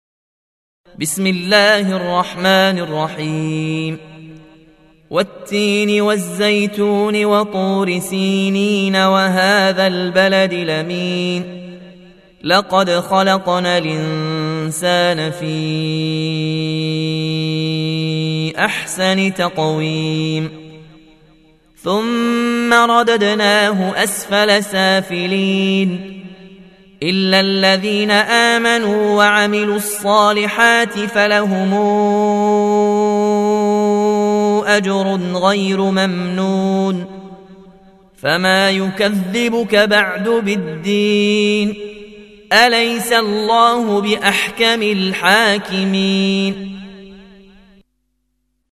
برواية ورش عن نافع